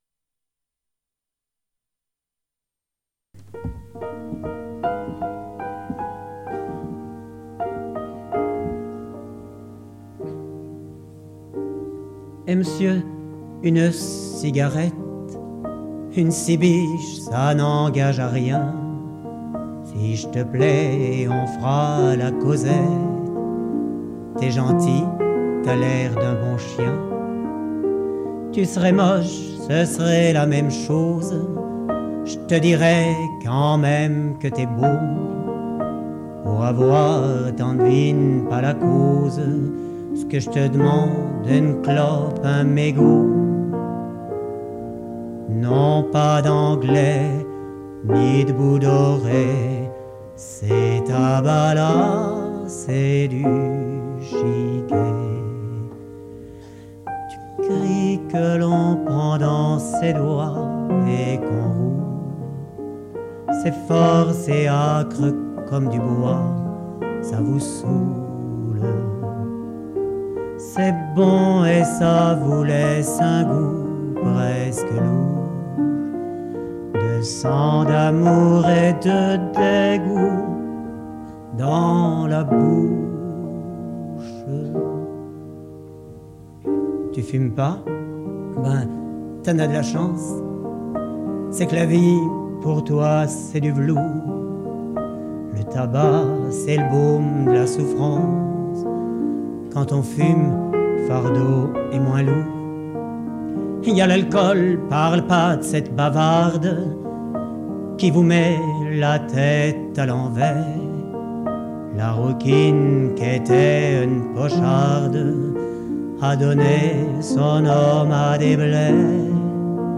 Tir�e du spectacle au Rendez-vous des ann�es 30
T�moignages en chansons
Enregistr�e en public (1985)
chanson r�aliste